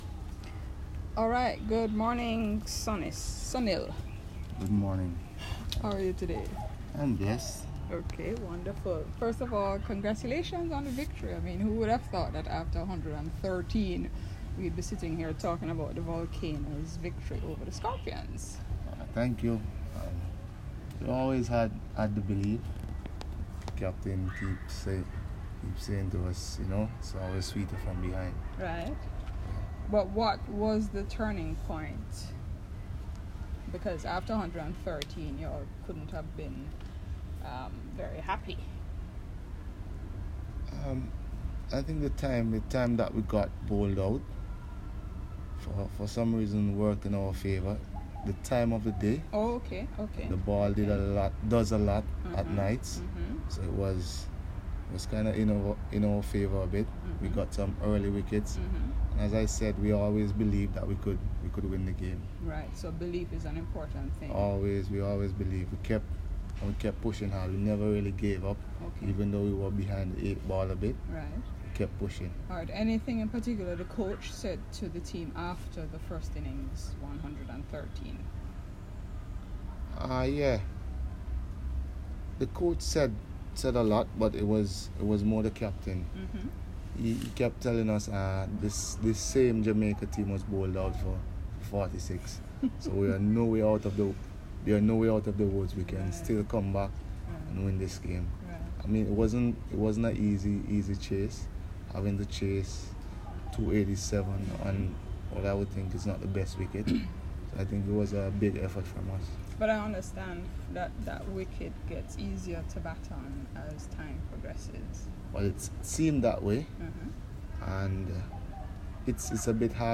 This is part 1 of the unedited audio interview.